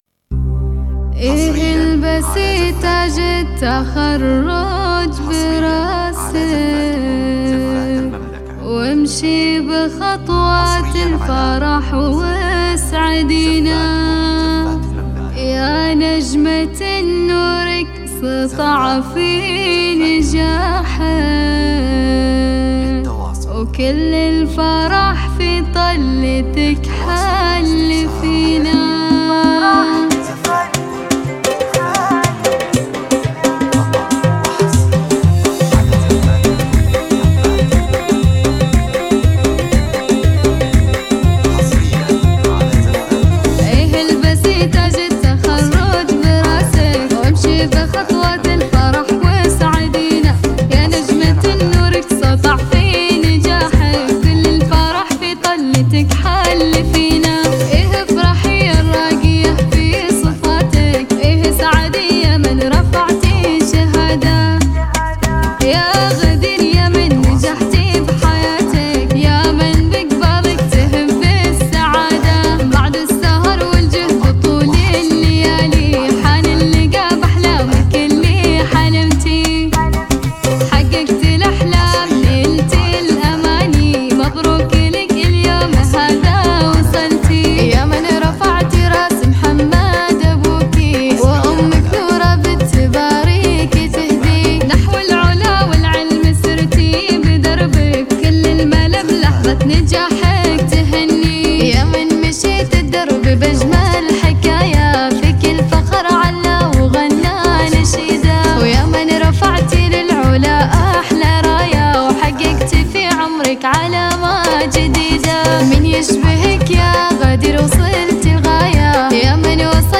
بإيقاع أنيق يليق بفخر التخرج.